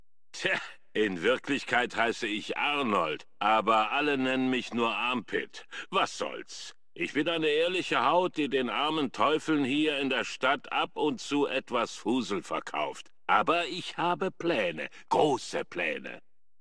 Fallout: Brotherhood of Steel: Audiodialoge
FOBOS-Dialog-Armpit-004.ogg